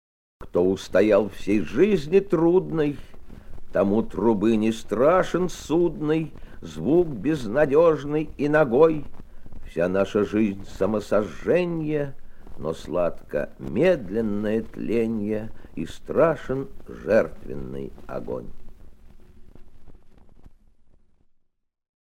3. «Давид Самойлов – Кто устоял в сей жизни трудной (читает автор)» /